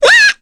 Rodina-Vox_Damage_kr_02.wav